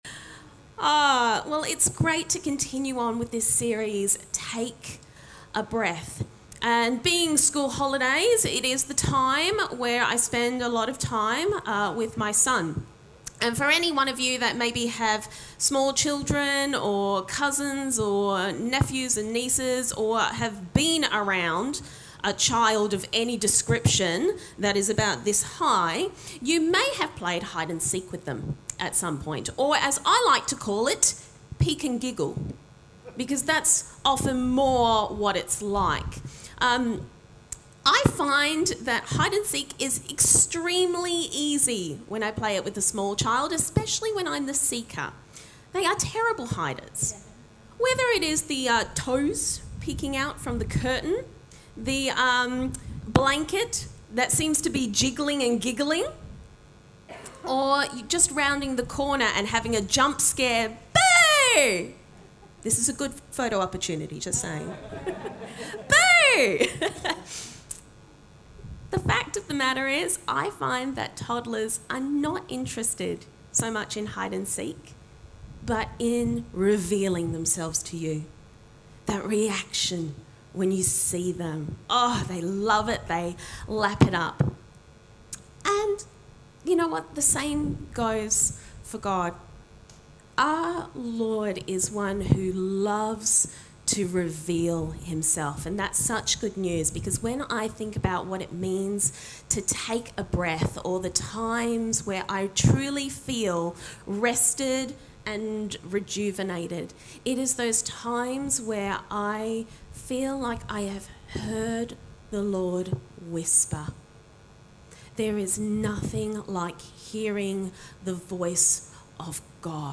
A message from the series "Take a Breath."